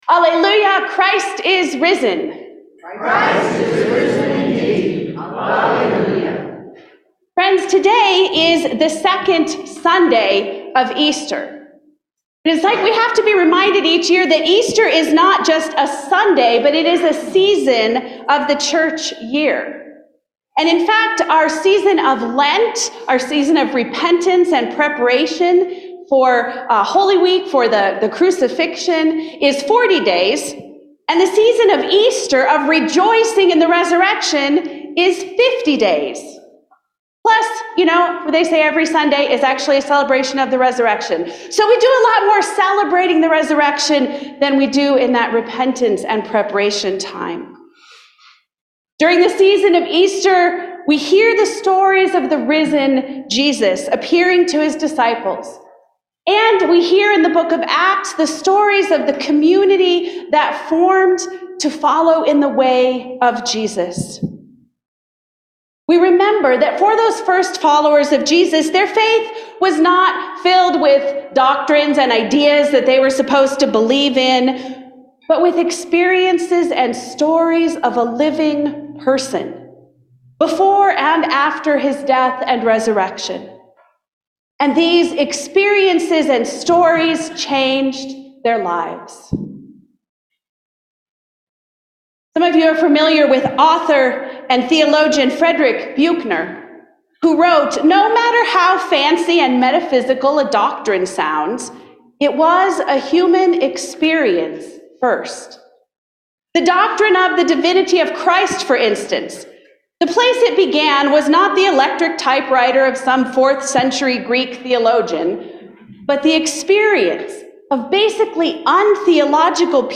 Passage: John 20:19-31 Service Type: Sunday Morning